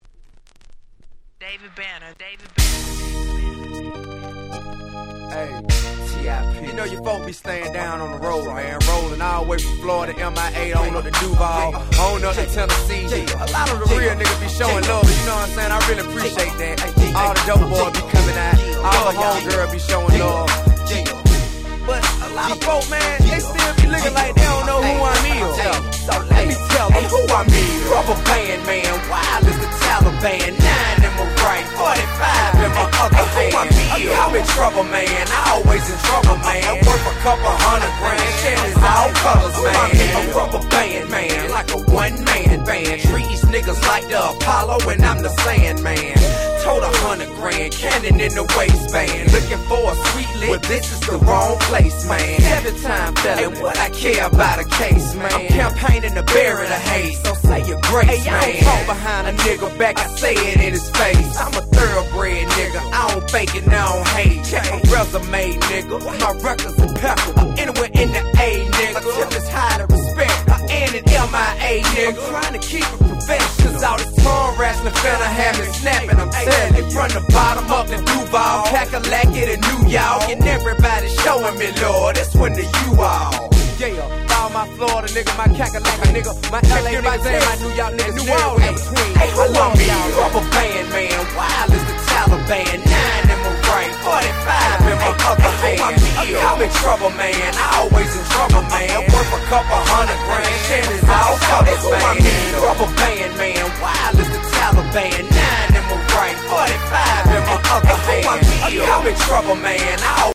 03' Smash Hit Southern Hip Hop !!